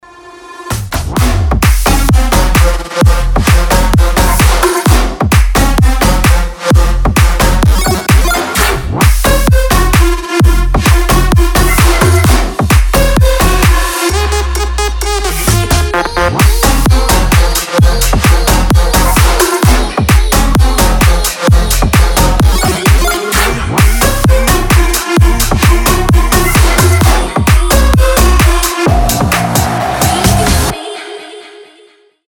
• Качество: 320, Stereo
громкие
Electronic
EDM
энергичные
Стиль: future house